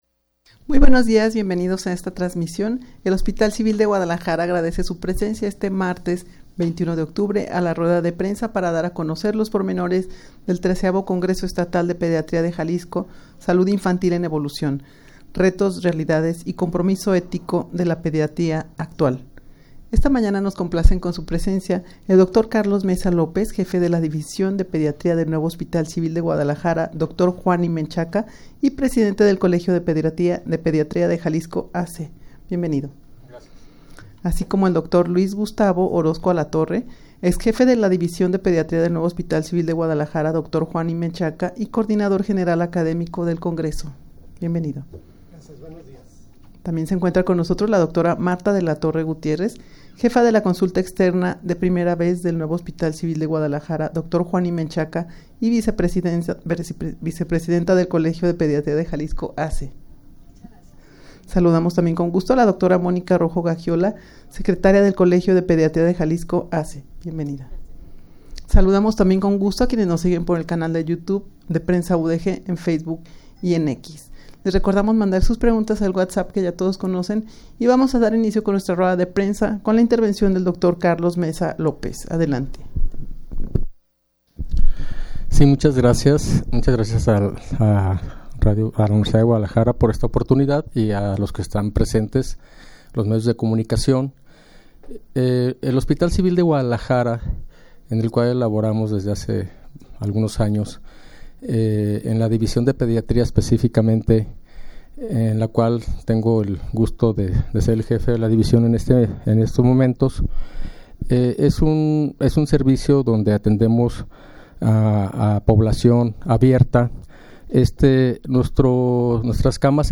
rueda-de-prensa-para-dar-a-conocer-los-pormenores-del-xiii-congreso-estatal-de-pediatria-de-jalisco-.mp3